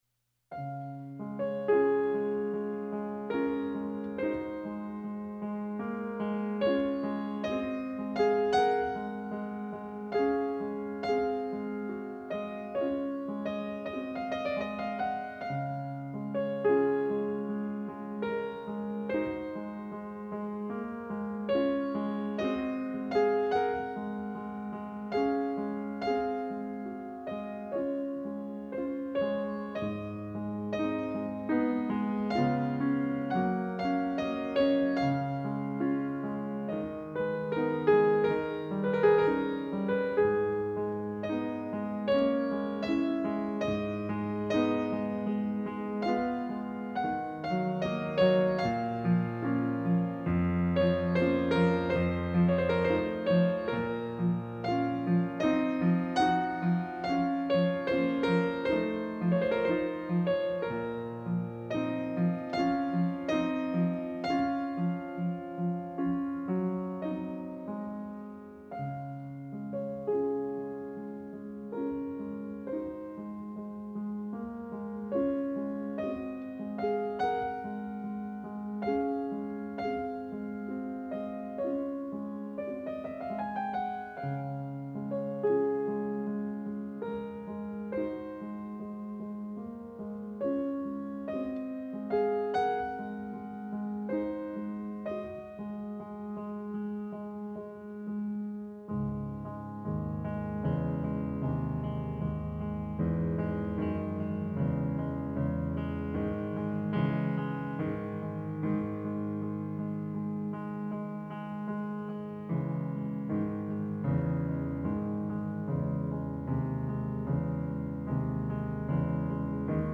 У Шопена есть -Капли дождя(Прелюдия  15 Ре бемоль мажор.)..тоже замечательно!